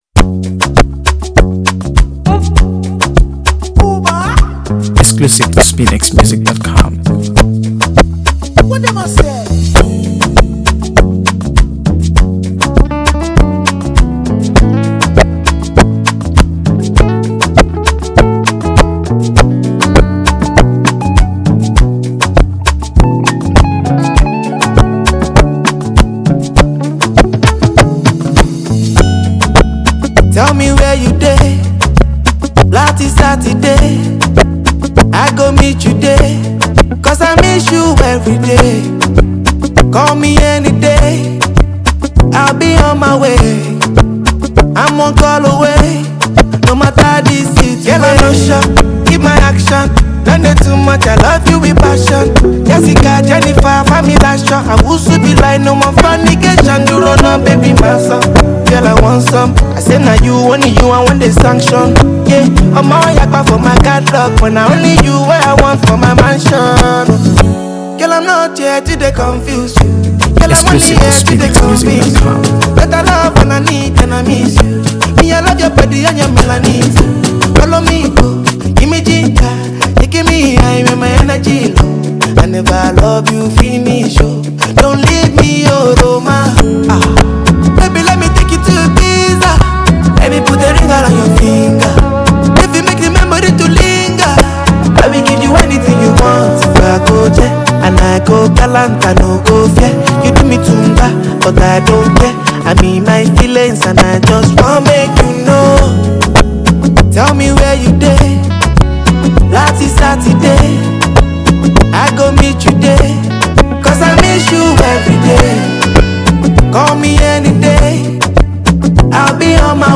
AfroBeats | AfroBeats songs
signature blend of soulful melodies and heartfelt lyrics